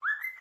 device-added.ogg